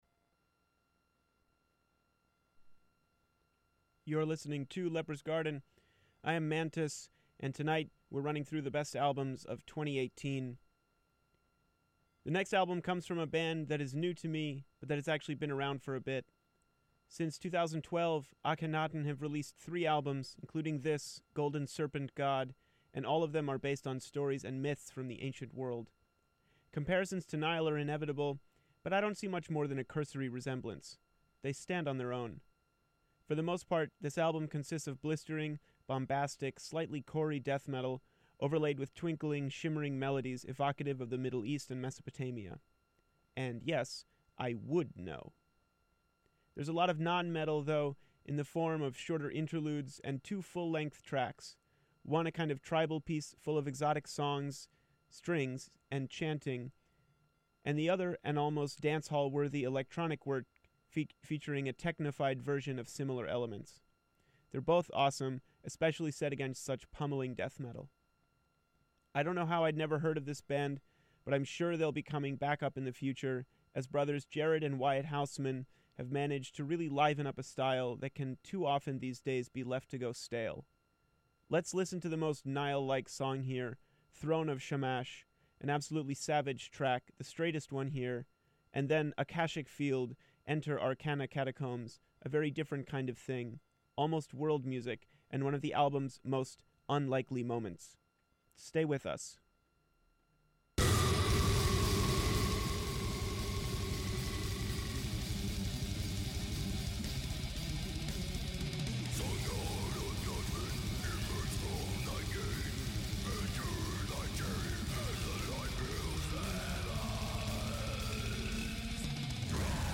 A historical and thematic study of death metal in all its forms.
Recorded in the studios of WMPG-FM in Portland, Maine.